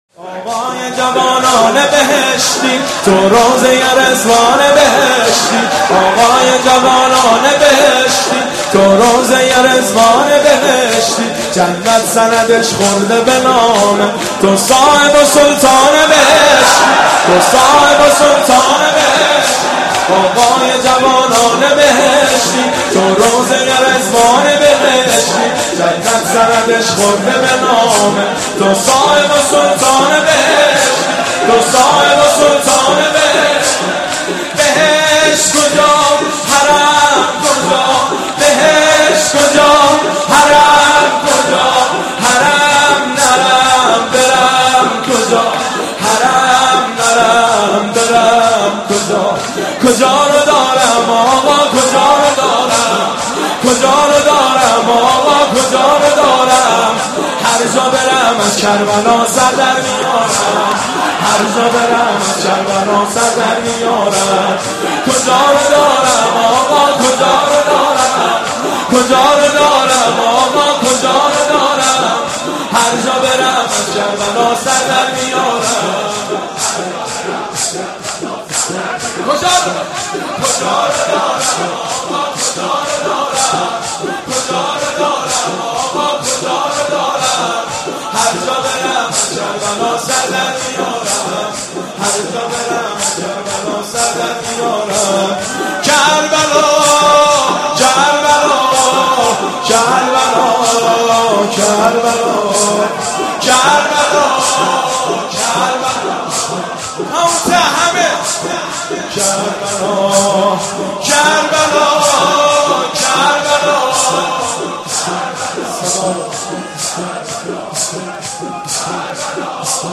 شب اول نوحه ی حضرت مسلم -